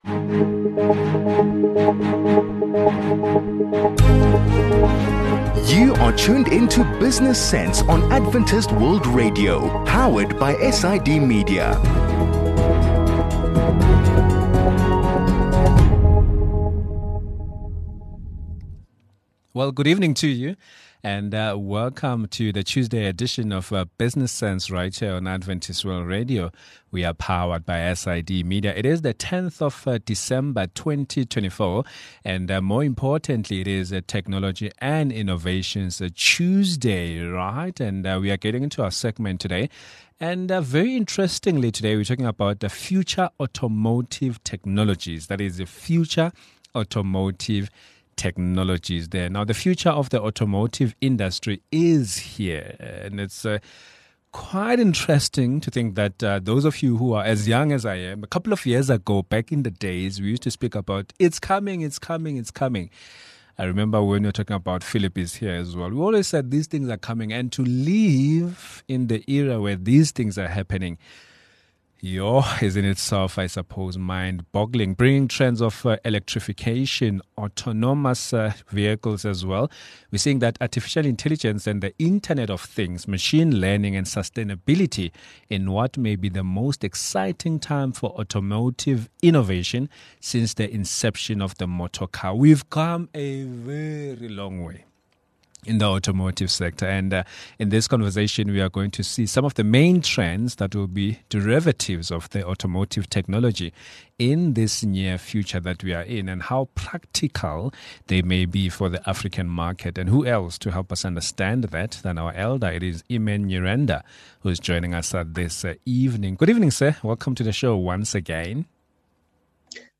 The future of the automotive industry is here. In this conversation, we are going to see some of the main trends that will be derivatives of automotive technology in the future.